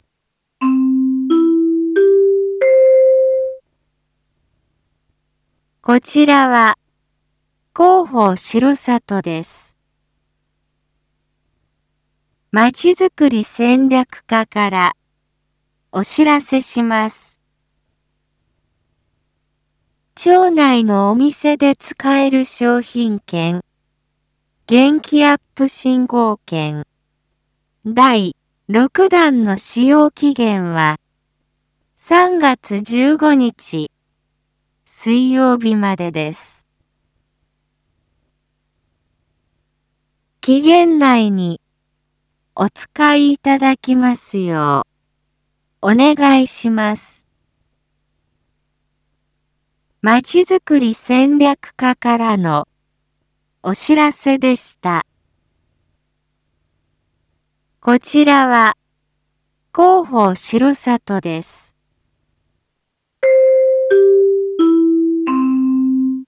一般放送情報
Back Home 一般放送情報 音声放送 再生 一般放送情報 登録日時：2023-03-09 19:01:07 タイトル：R5.3.9 19時放送分 インフォメーション：こちらは、広報しろさとです。